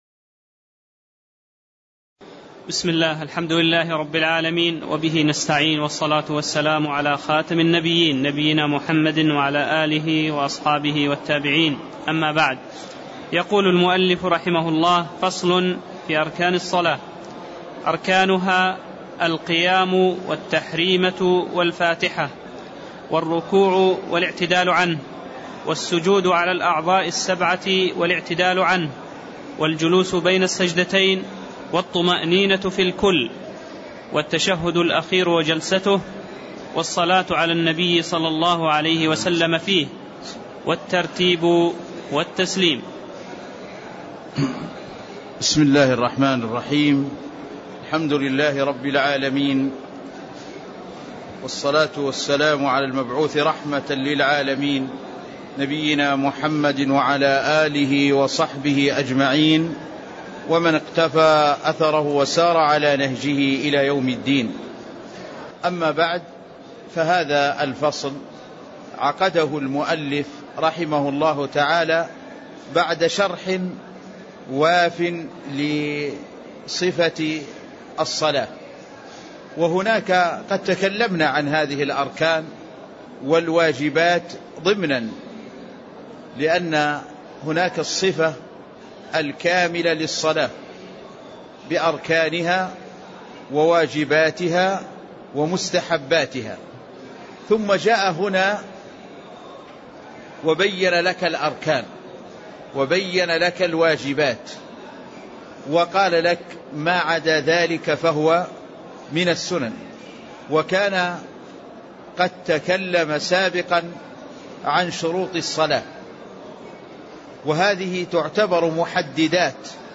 تاريخ النشر ١٢ ذو القعدة ١٤٣٥ هـ المكان: المسجد النبوي الشيخ